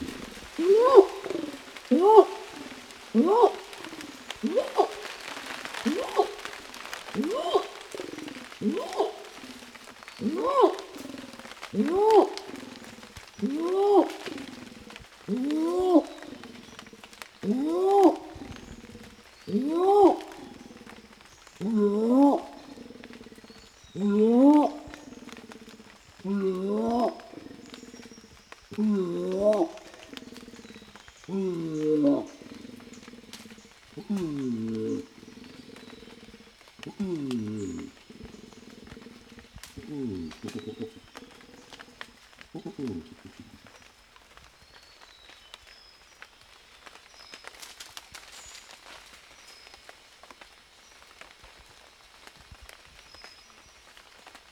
Nur dominante Männchen, die bereits Backenwülste entwickelt haben, kommunizieren über lange Distanzen mit «Long calls», um untergeordnete Männchen zu vertreiben und Weibchen für die Paarung anzulocken.
Long call eines männlichen Orang-Utans